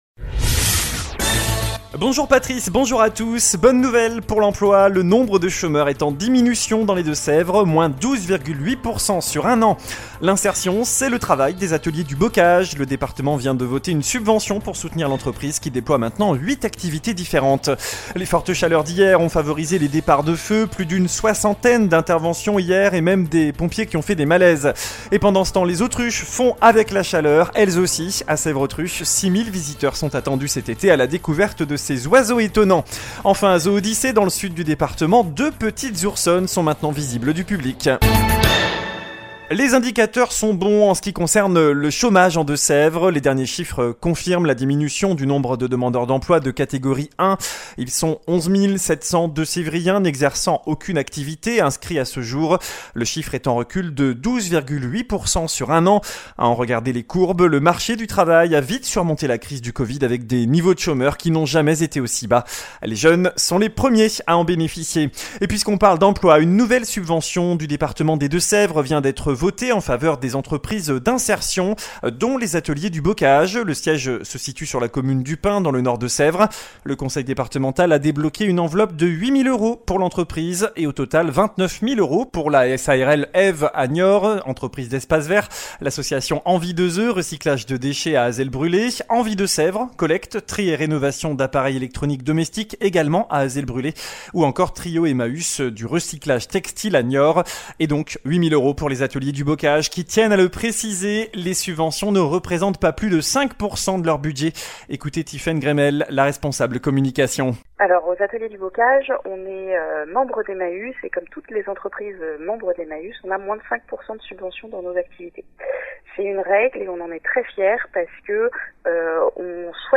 JOURNAL DU JEUDI 04 AOÛT